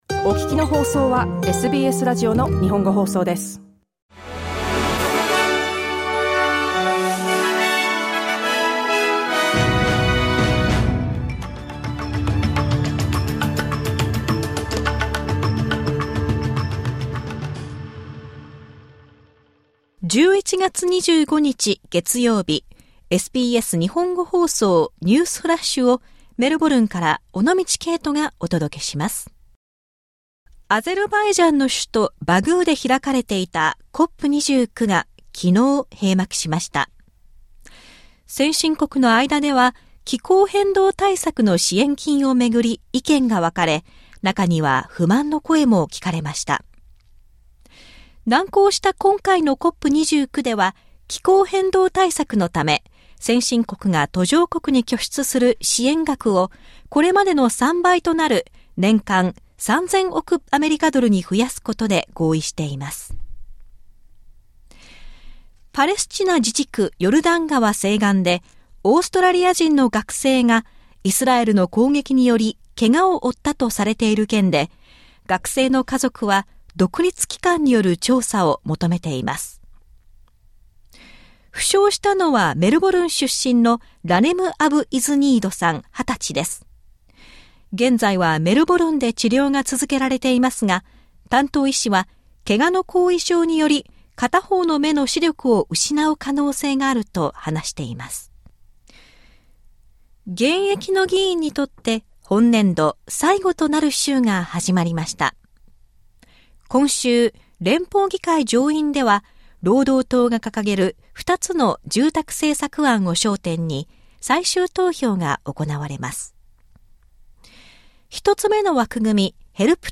SBS日本語放送ニュースフラッシュ 11月25日 月曜日